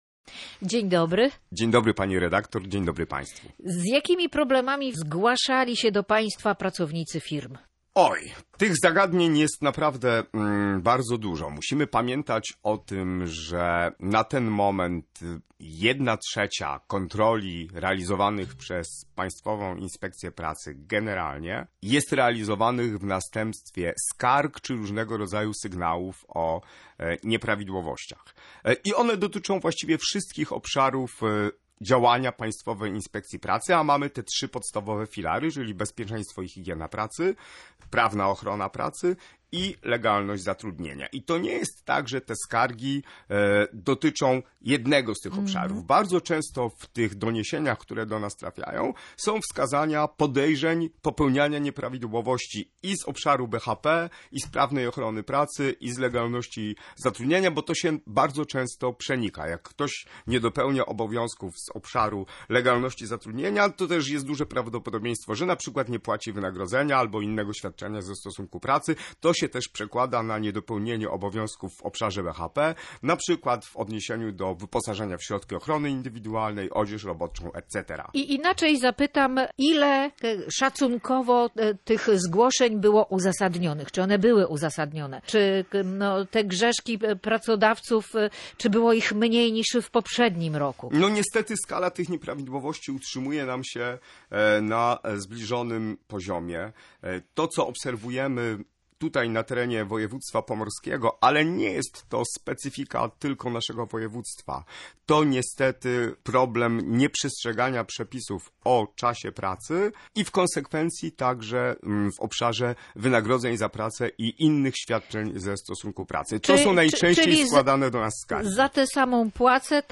Jak mówił na antenie Radia Gdańsk Okręgowy Inspektor Pracy, na Pomorzu Dariusz Górski, skala nieprawidłowości była na podobnym poziomie, jak w 2024 roku.